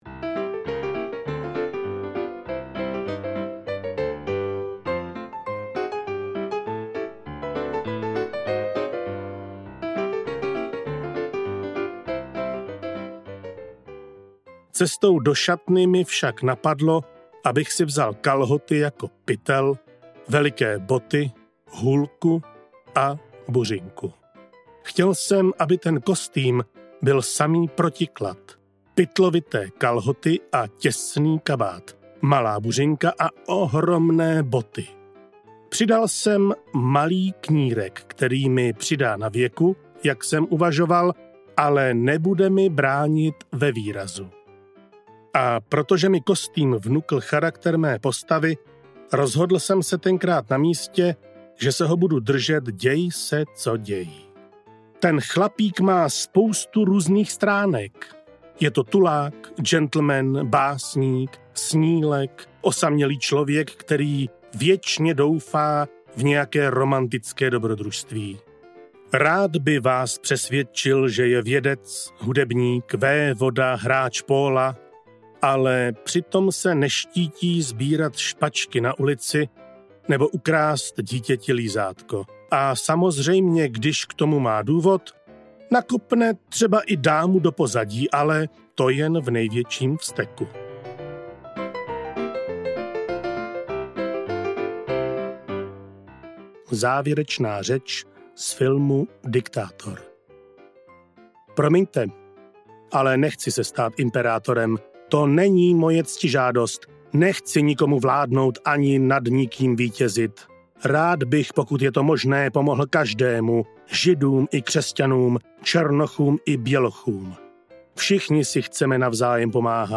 Charlie Chaplin: Můj životopis audiokniha
Ukázka z knihy
charlie-chaplin-muj-zivotopis-audiokniha